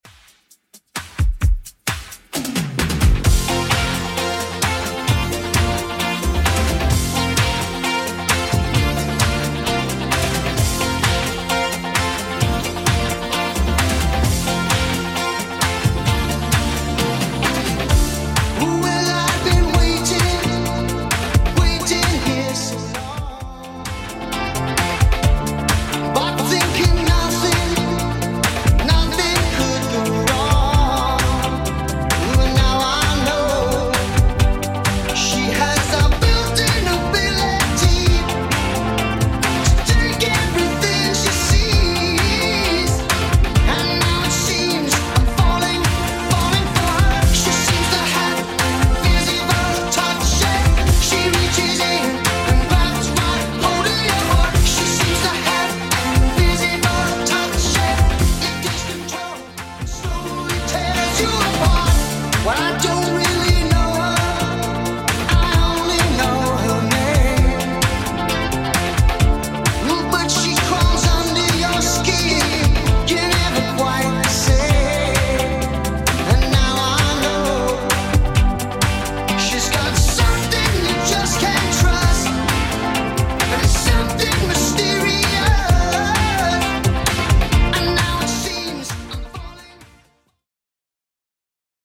Genre: 80's
BPM: 126